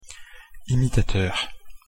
Oral (not nasal)
“im” + vowelimitateurimitatœʀ
imitateur-pronunciation.mp3